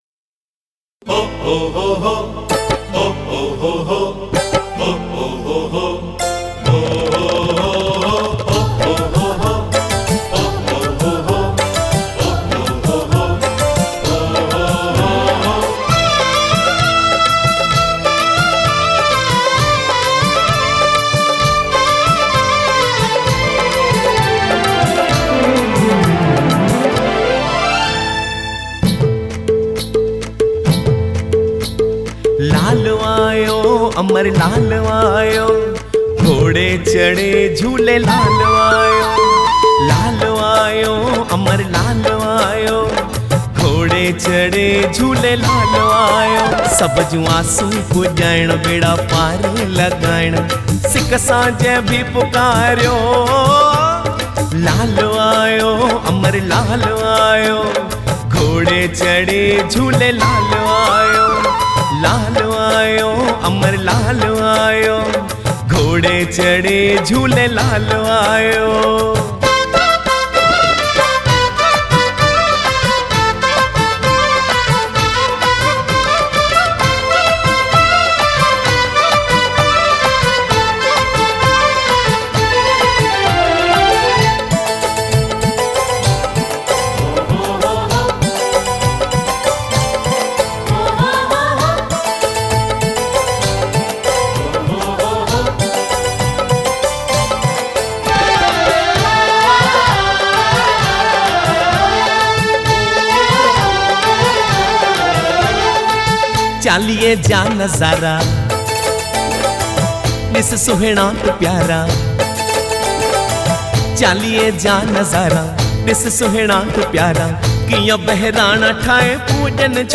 Jhulelal Songs